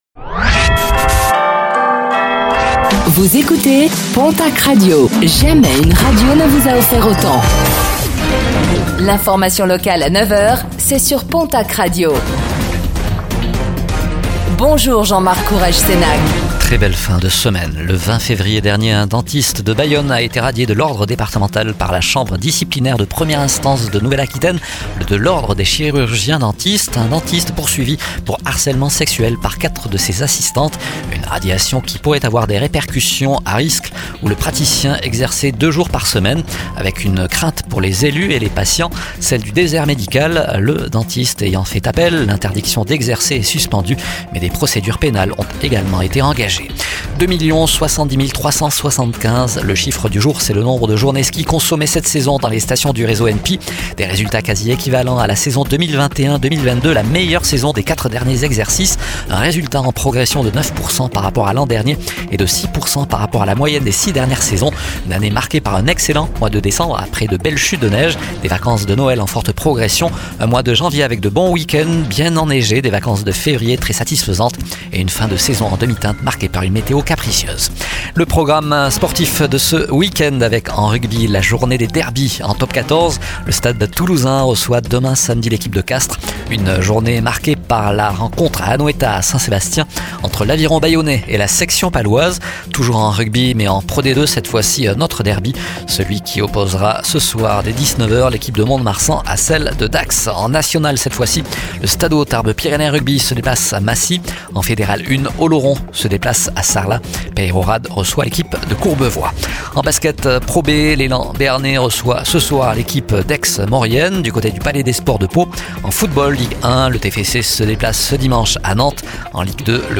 Infos | Vendredi 25 avril 2025